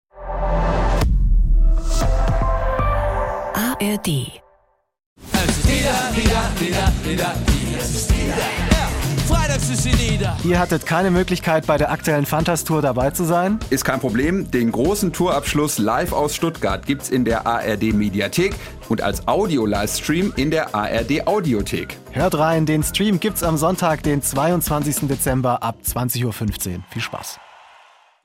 live aus Stuttgart